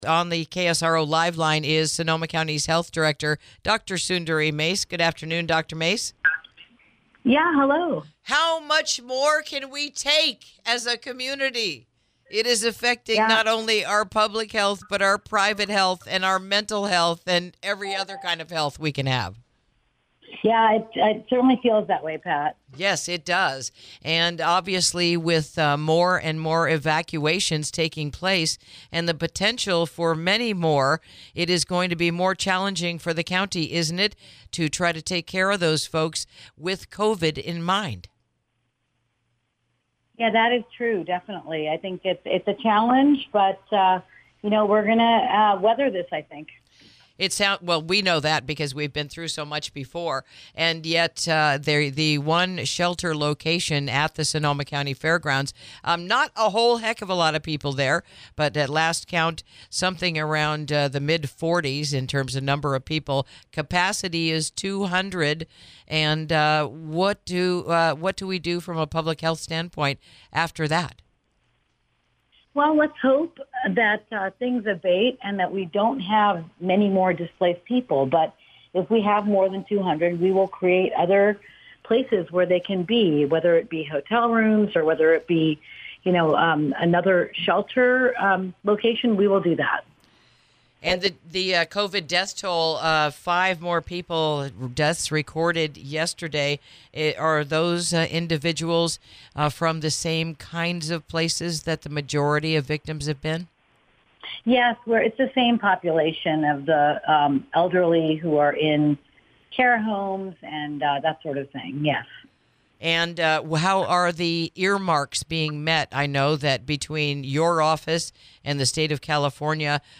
INTERVIEW: Another 5 COVID-19 Deaths in Sonoma County